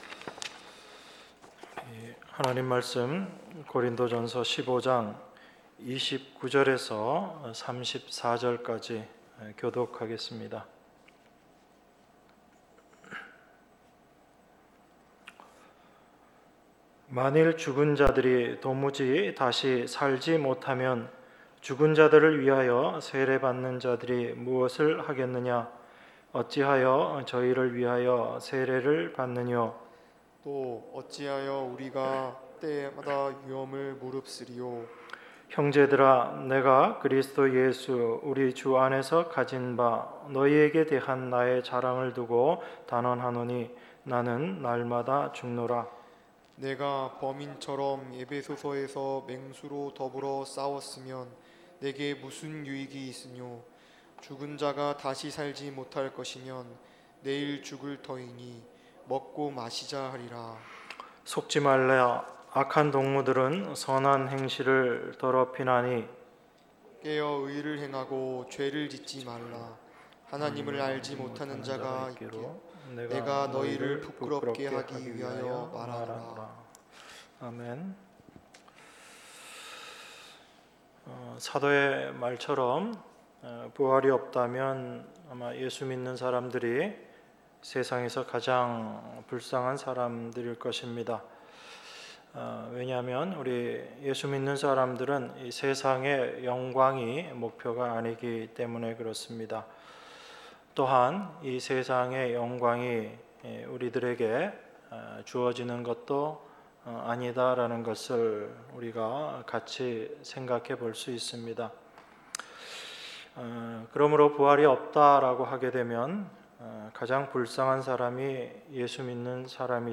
주일1부